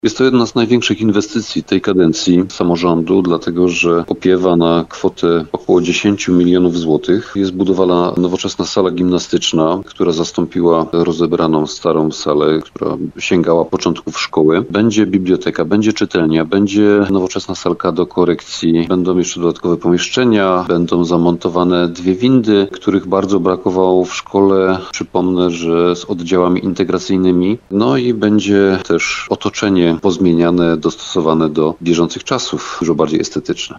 - To jedna z największych inwestycji tej kadencji samorządu - mówi Krystian Grzesica, burmistrz Bierunia.